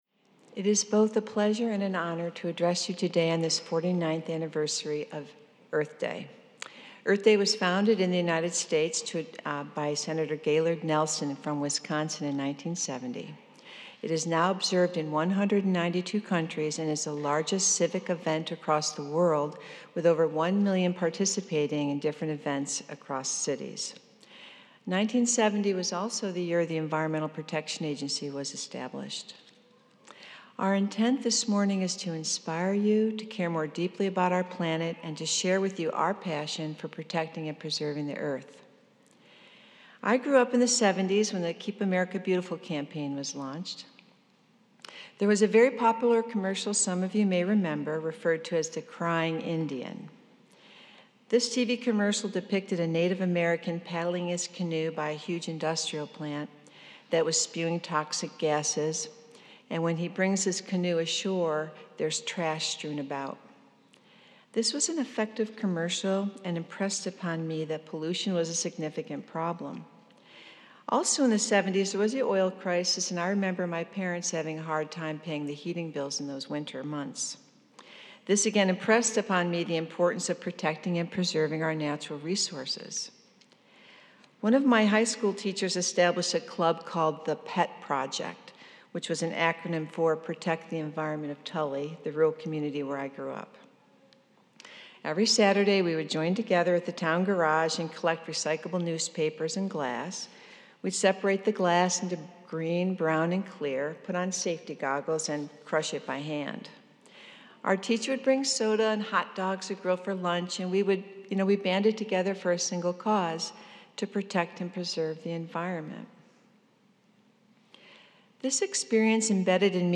Homily Transcript It is both our pleasure and honor to address you today on this 49th celebration of Earth Day.